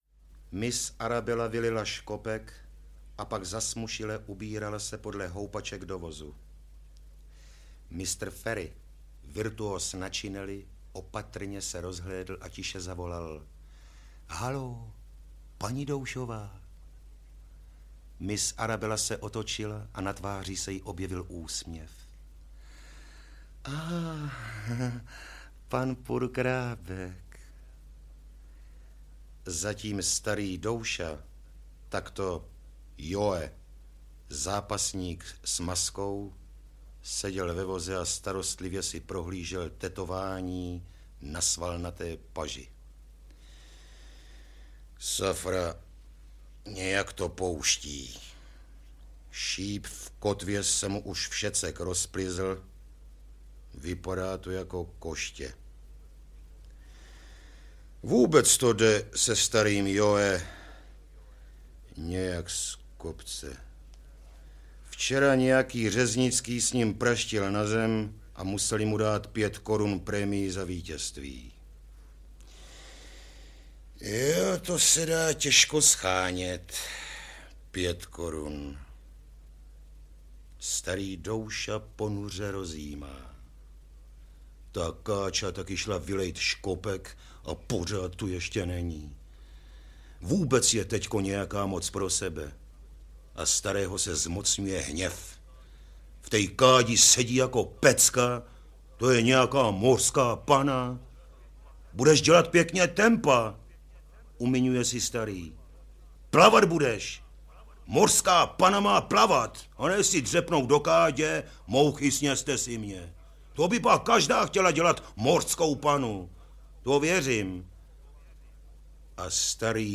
Supraphon vypravuje...3 (Werich, Suchý, Němec, Saint-Exupéry, Poláček, Čapek) - František Němec - Audiokniha
• Čte: Jan Pivec